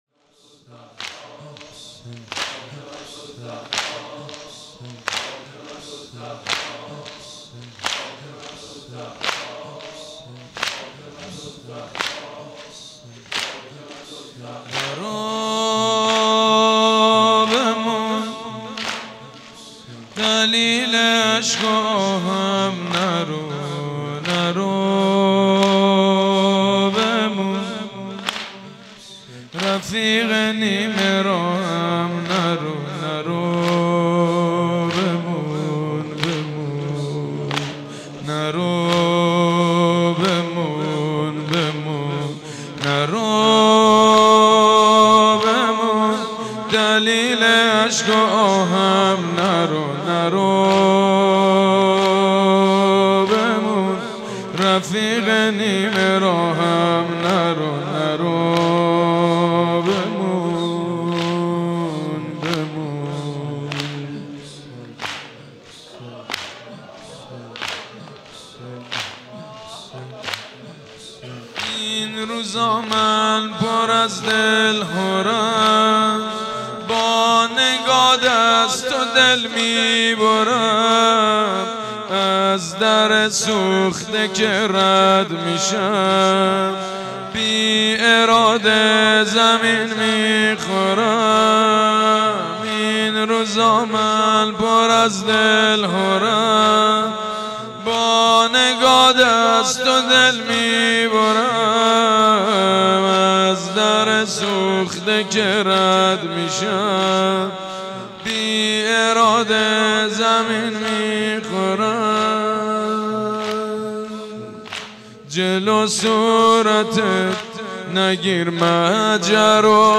مداح
قالب : زمینه